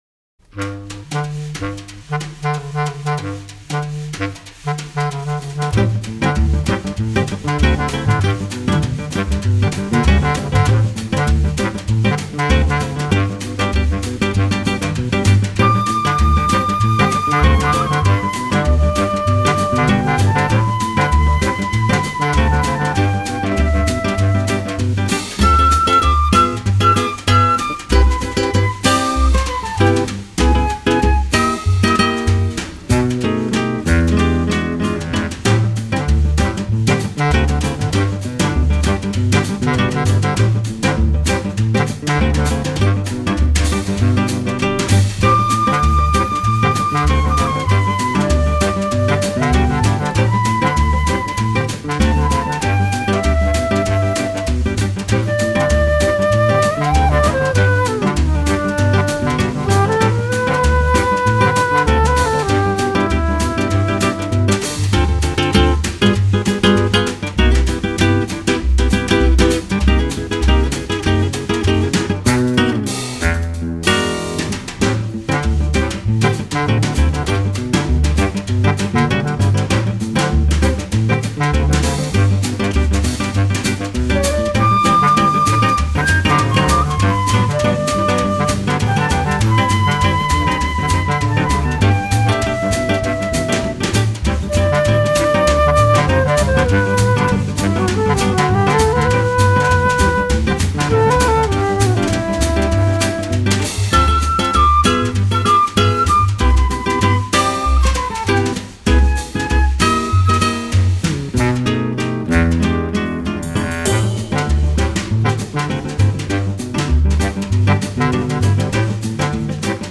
unknown piano player